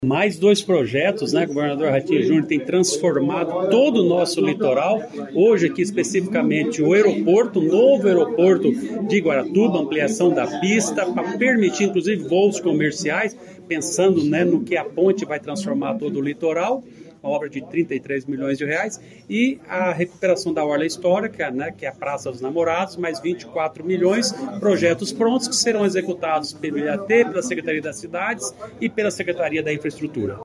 Sonora do secretário do Planejamento, Ulisses Maia, sobre os novos investimentos em Guaratuba